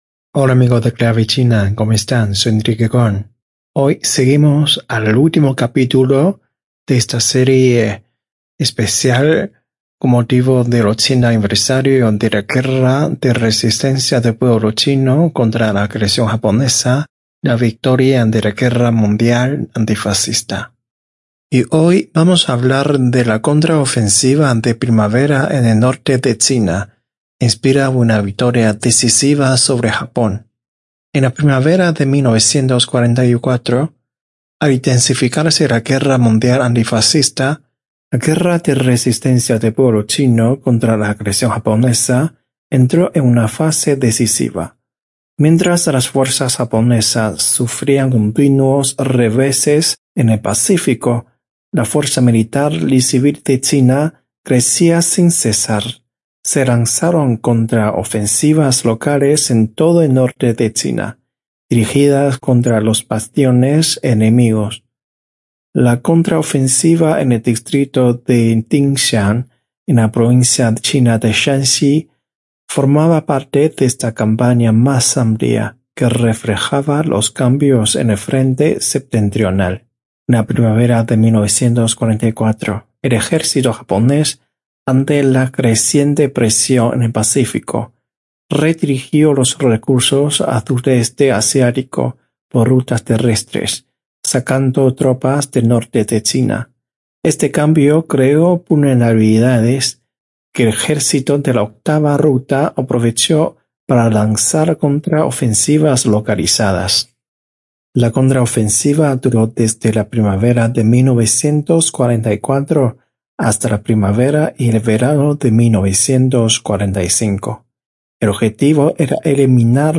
Estos 4 episodios fueron emitidos en nuestro programa radial, Clave China, los días 17, 24 y 31 de Agosto y 7 de septiembre del 2025